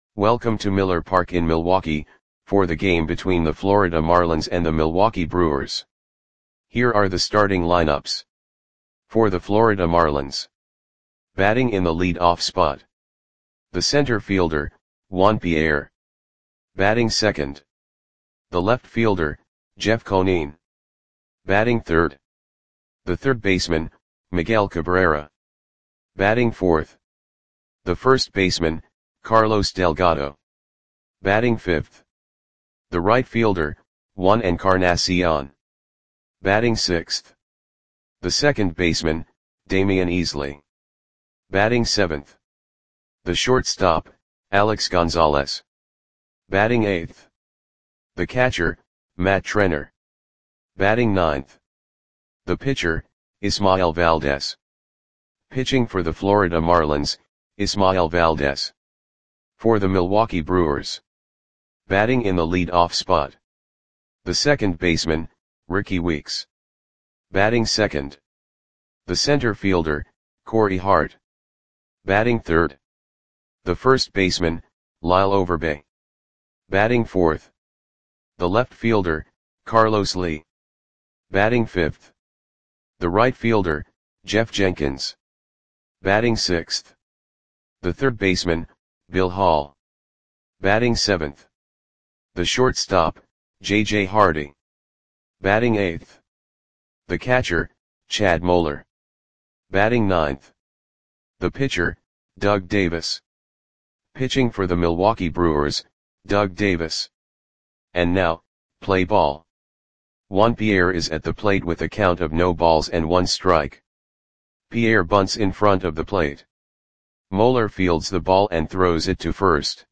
Audio Play-by-Play for Milwaukee Brewers on August 25, 2005
Click the button below to listen to the audio play-by-play.